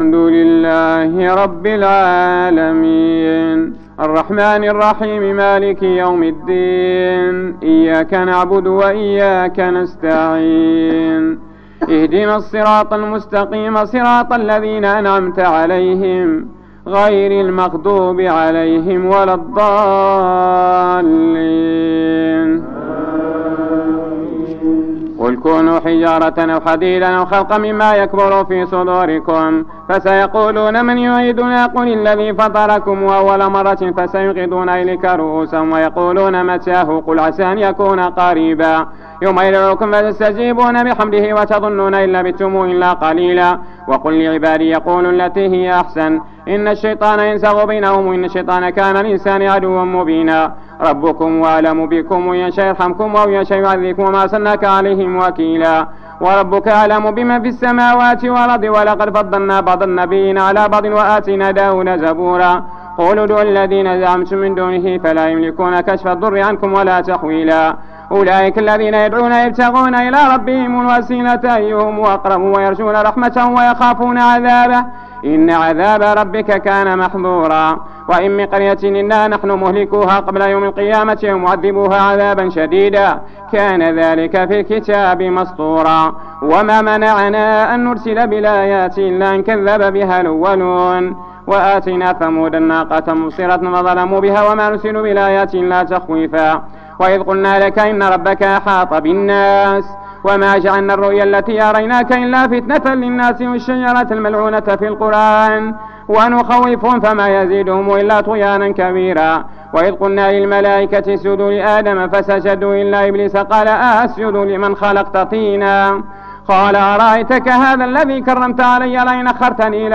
صلاة التراويح رقم 04 بمسجد ابي بكر الصديق فقارة الزوى